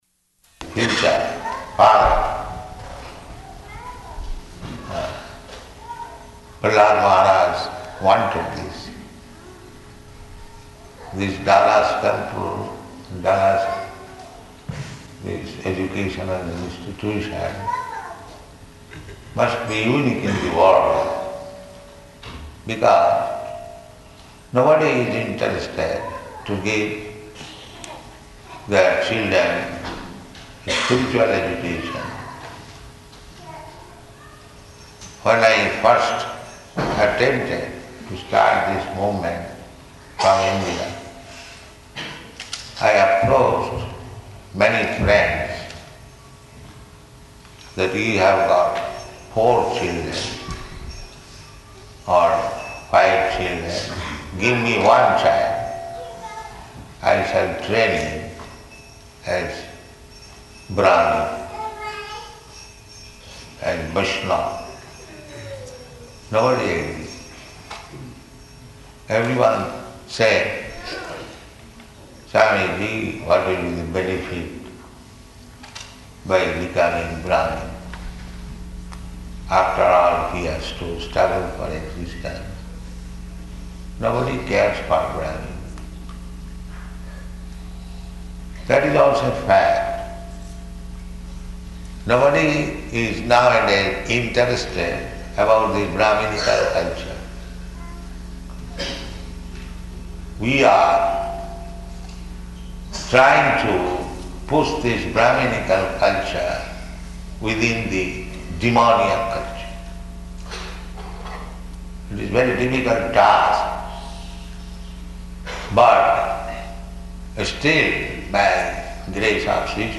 Lecture
Lecture --:-- --:-- Type: Lectures and Addresses Dated: March 3rd 1975 Location: Dallas Audio file: 750303LE.DAL.mp3 Prabhupāda: ...future [indistinct].